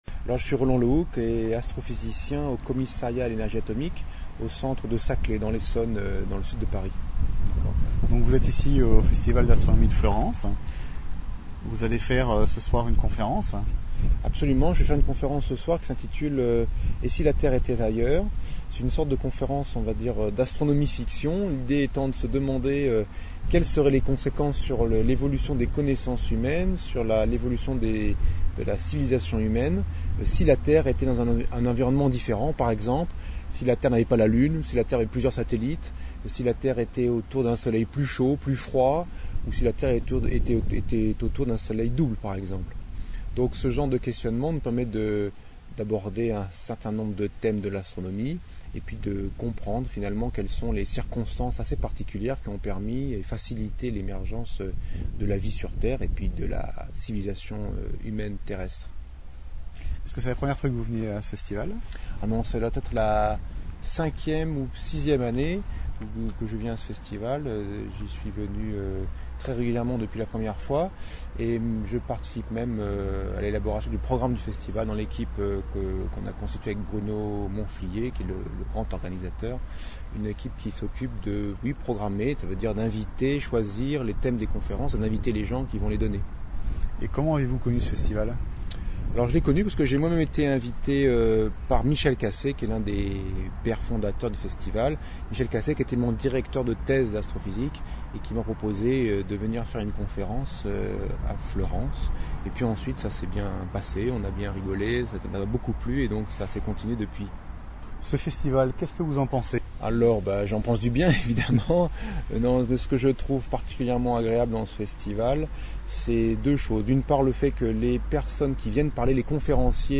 XVIIème festival d’astronomie de Fleurance